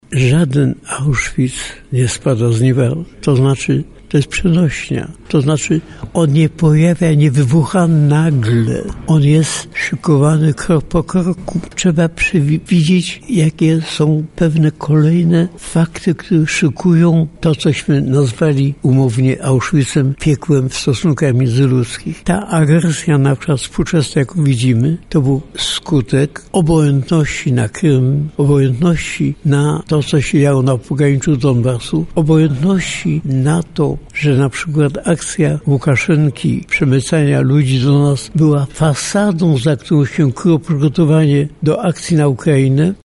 W 2022 roku Marian Turski był gościem Akademickiego Radia Centrum.